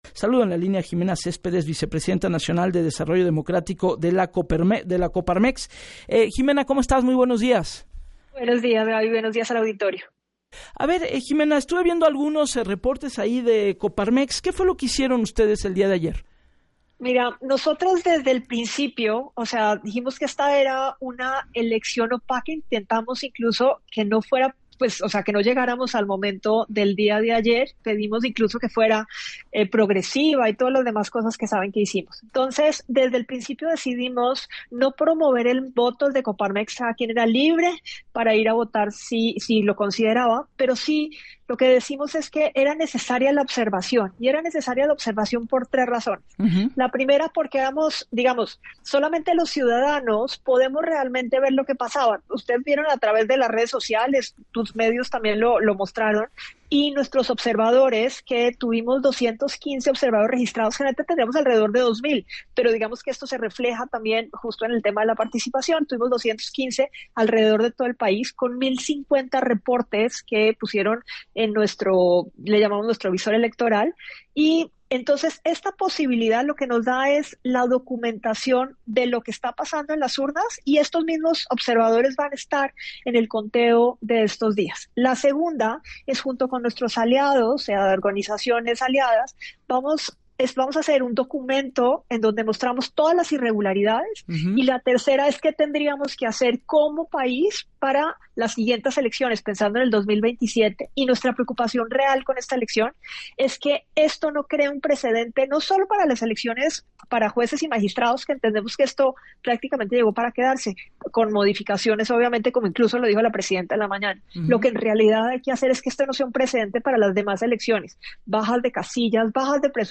“Desde el principio dijimos que esta era una elección opaca, decidimos no promover el voto desde Coparmex, y que cada quien era libre de ir a votar si o consideraba”, recordó en el espacio de “Así las Cosas” con Gabriela Warkentin.